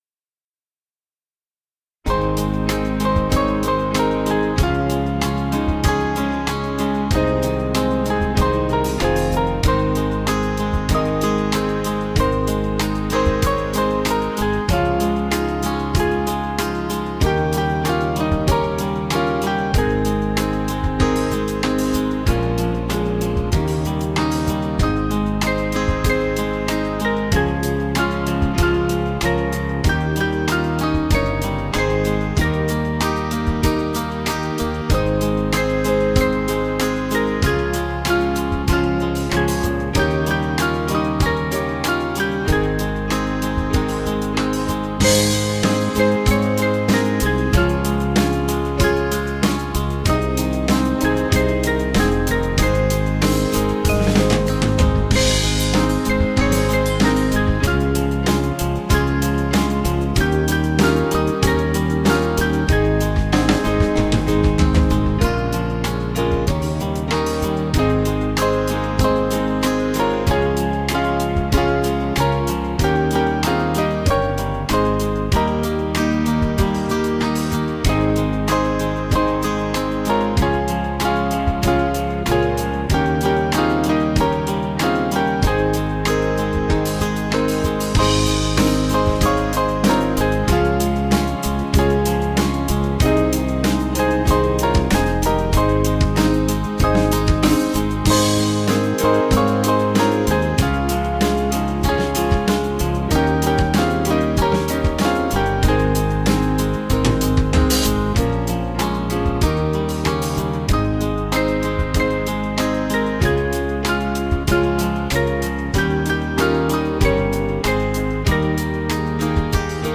Small Band
(CM)   4/Ab